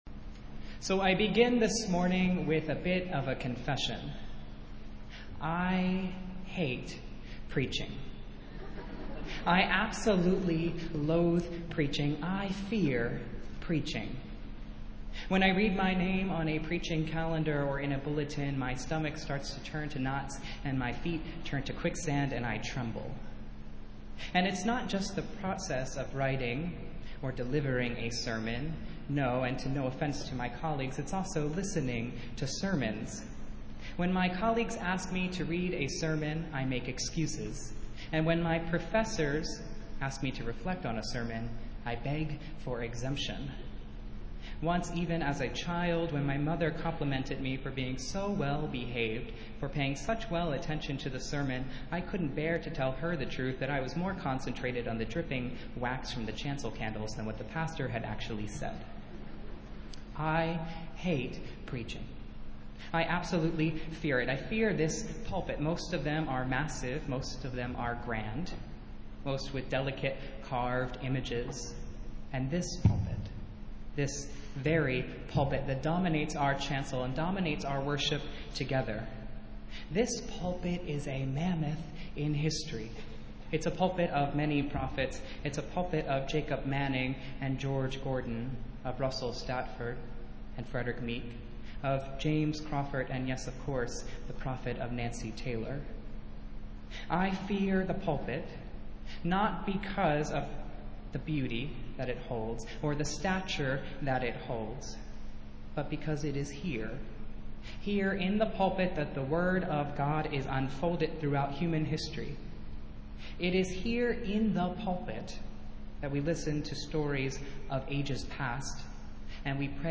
Festival Worship - Seventh Sunday after Pentecost